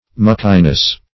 \Muck"i*ness\ (m[u^]k"[-e]*n[e^]s)
muckiness.mp3